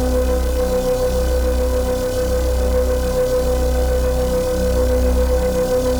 Index of /musicradar/dystopian-drone-samples/Non Tempo Loops
DD_LoopDrone1-B.wav